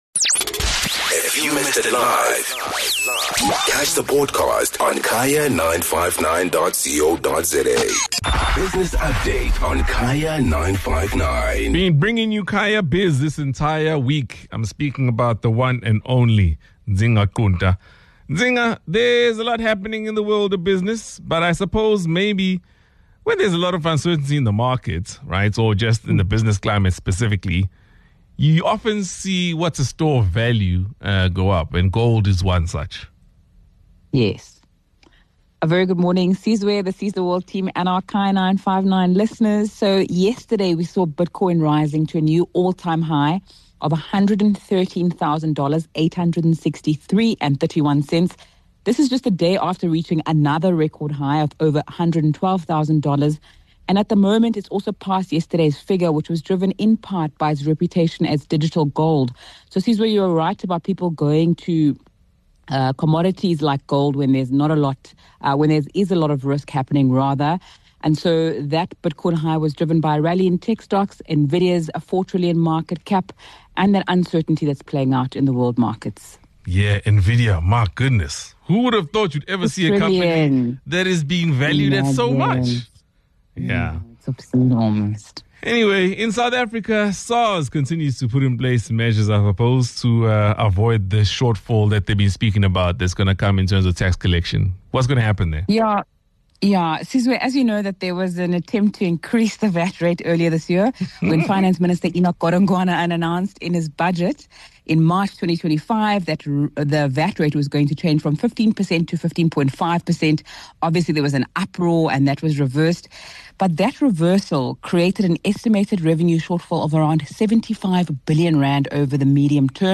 11 Jul Business Update - Bitcoin new all-time high & SARS collection measures.